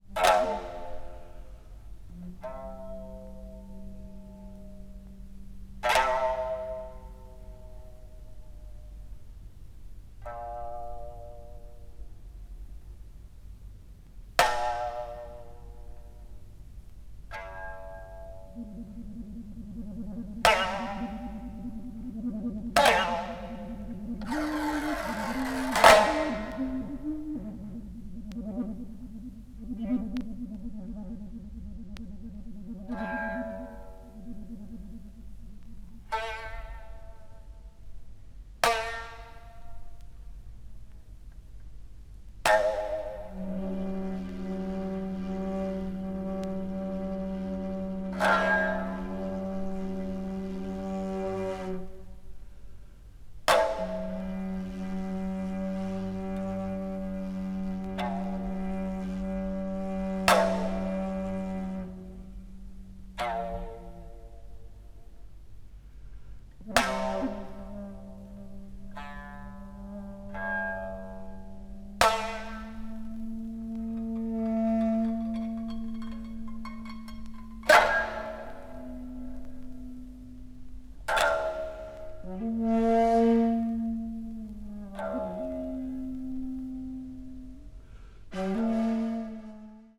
media : EX/EX(わずかにチリノイズが入る箇所あり)
即興的要素が強く、日本古来の独特な精神を現代に昇華させた緊張感のある素晴らしい演奏です。